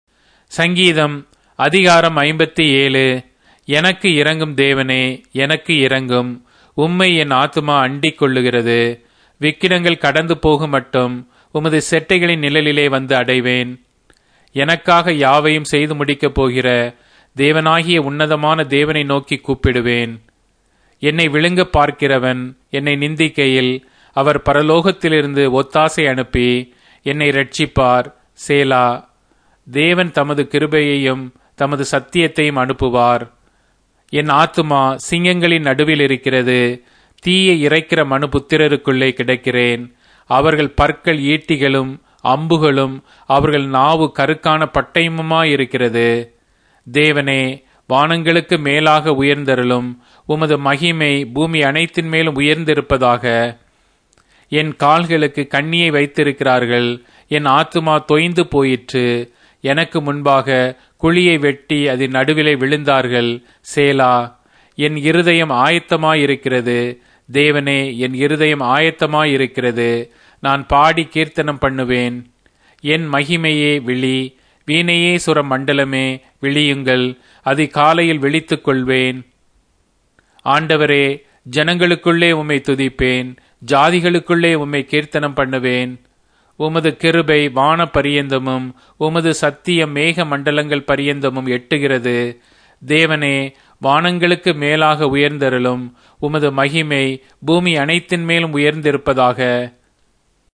Tamil Audio Bible - Psalms 74 in Erven bible version